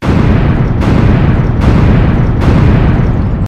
Mixtape Effect.wav